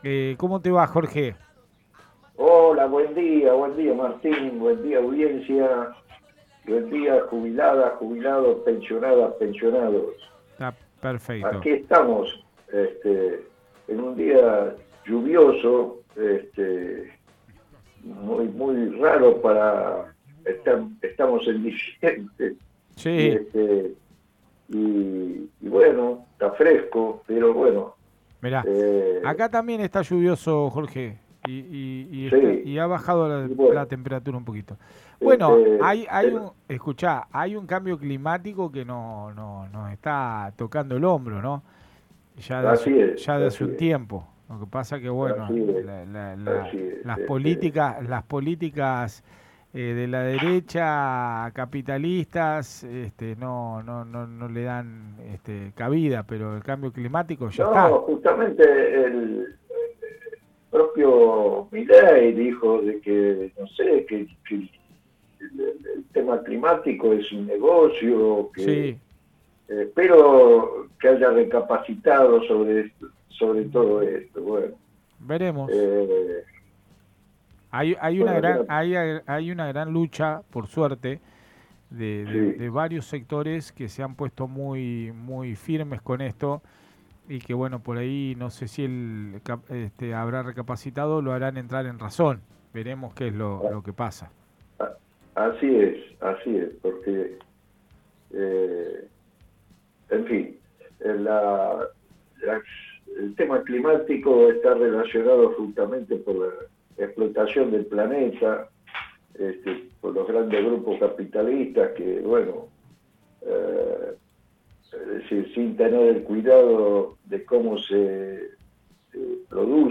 COLUMNA DE JUBILADES